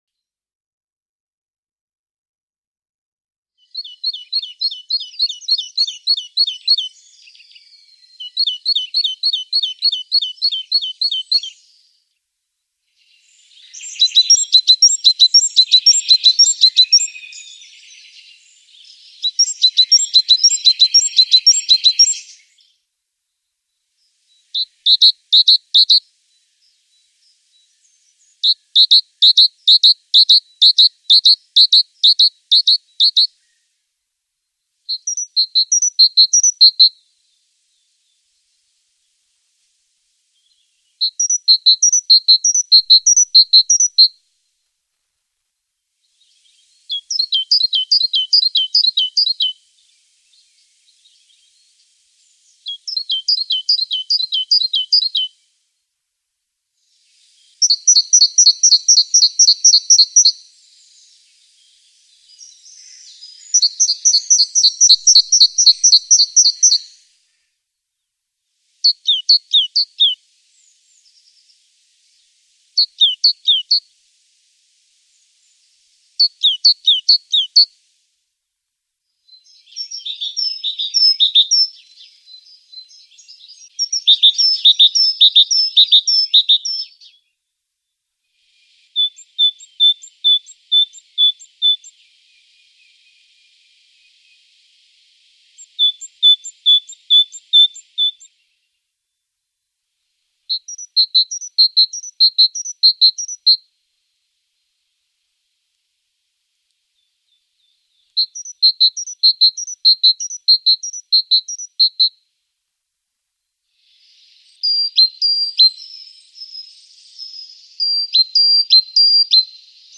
Kohlmeise